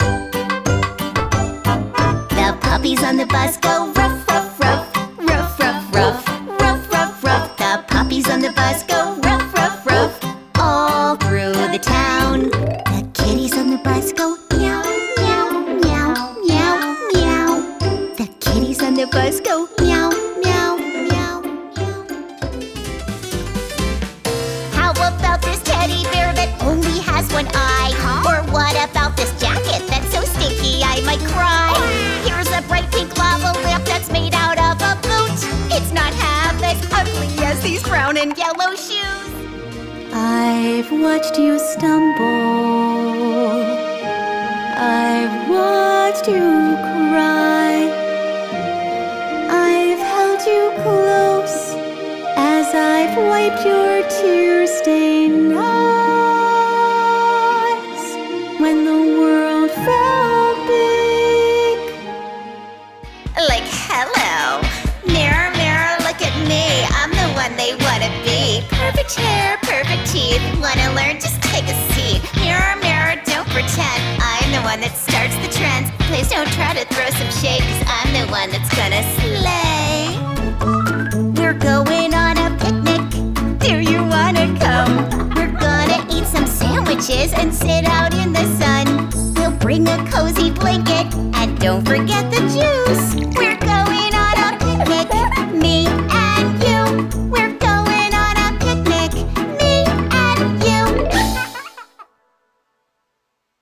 Kids' Media Singing Showreel
Female
Bright
Friendly
Warm